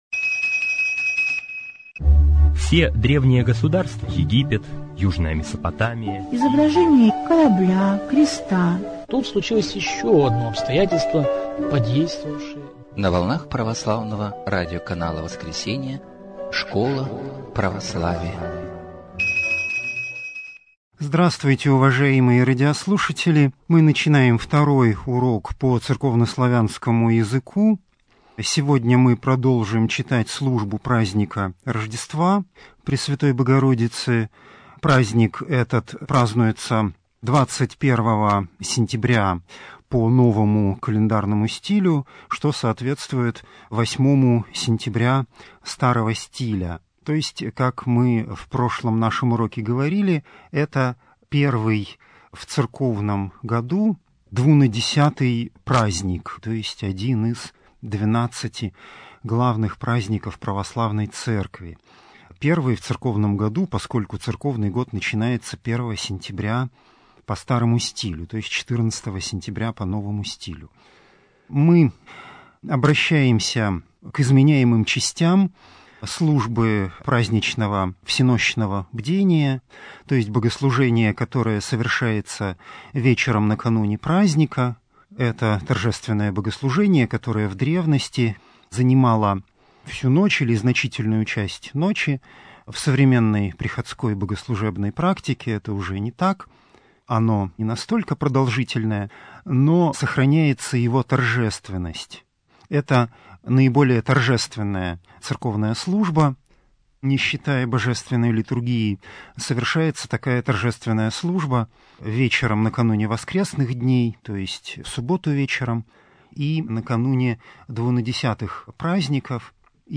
Церковно-славянский язык 2025-26. Урок 2